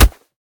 kick1.ogg